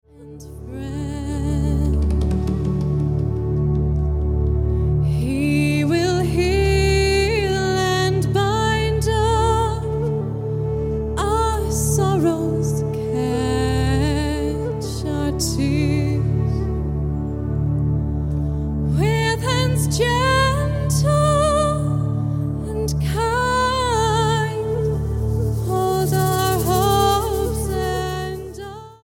STYLE: Celtic
utterly haunting Irish air